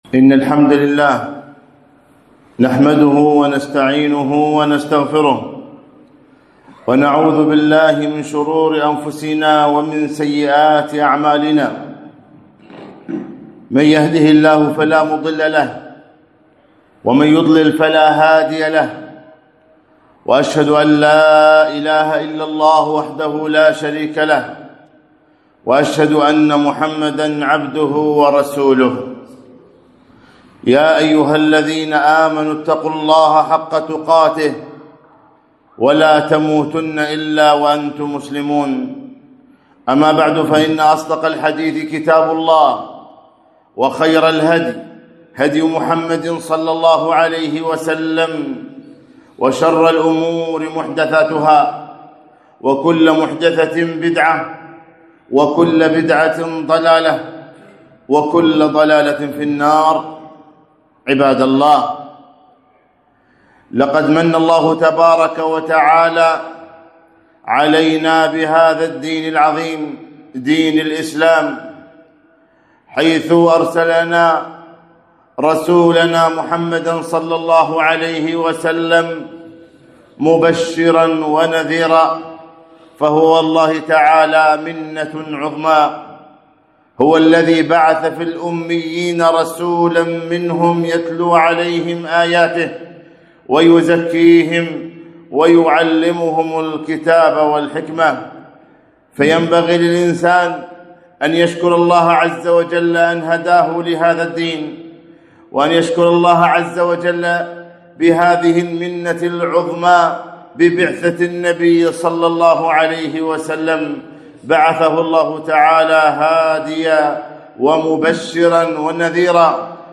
خطبة - حبهم إيمان وإحسان